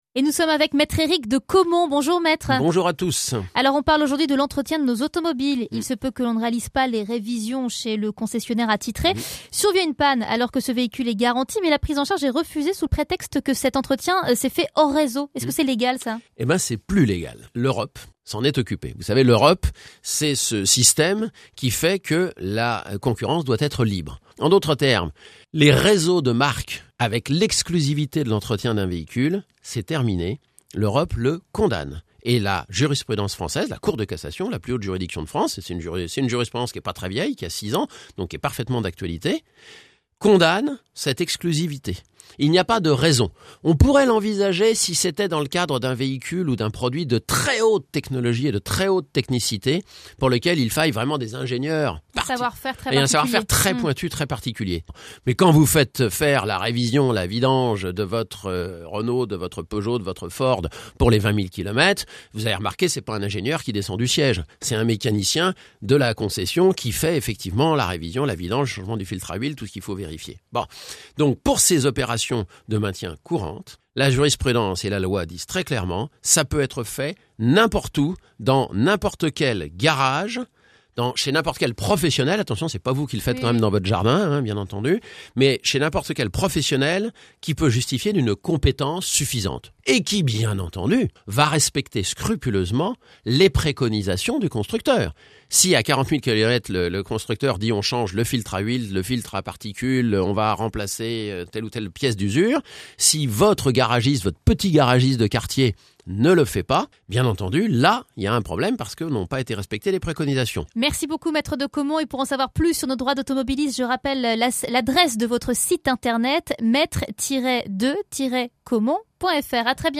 Chronique du 02/12/2012 – Entretien hors réseau